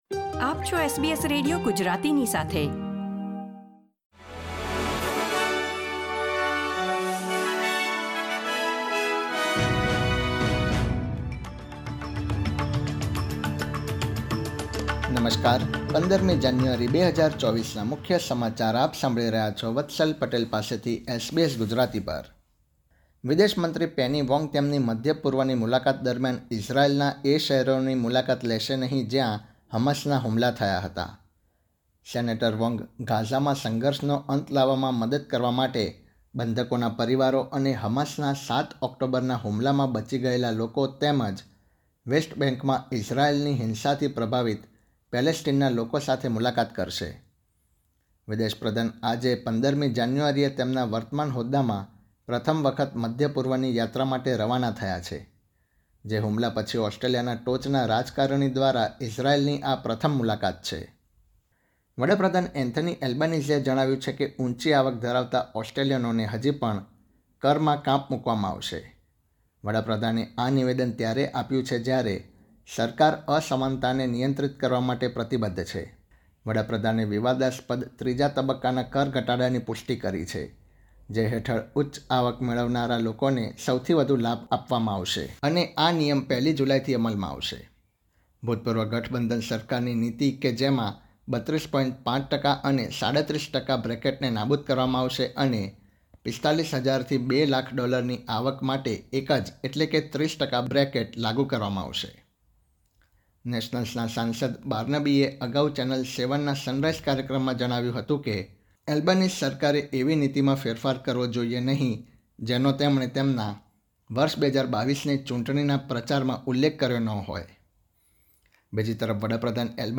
SBS Gujarati News Bulletin 15 January 2024